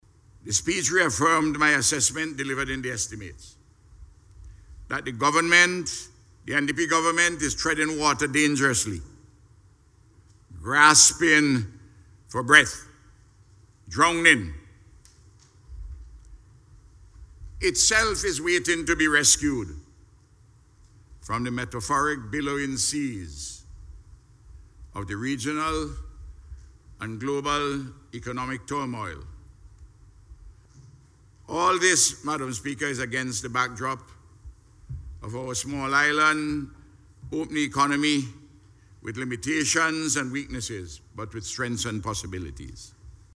In his response to the debate, Leader of the Opposition, Dr. The Hon. Ralph Gonsalves expressed concern about the National Budget in Parliament this morning.